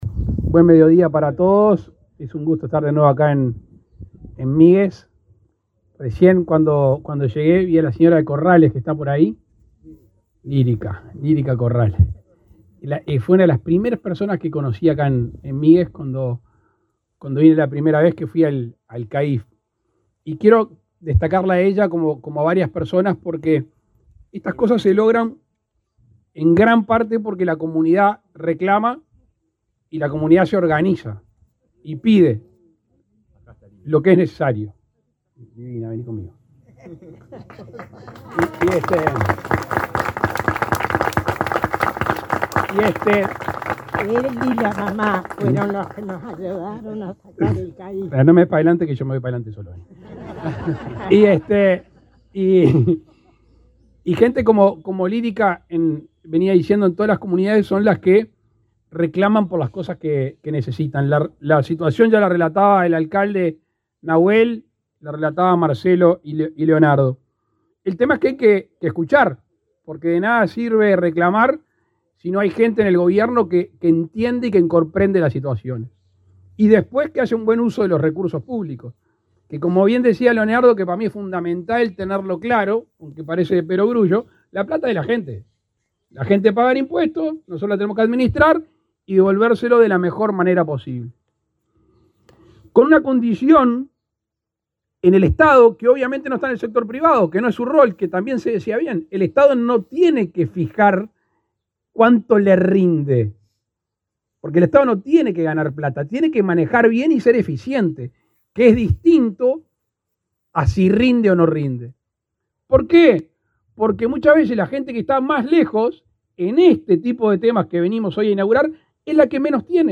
Palabras del presidente Luis Lacalle Pou
El presidente de la República, Luis Lacalle Pou, participó este jueves 26 en la inauguración de la base del Sistema de Atención Médica de Emergencia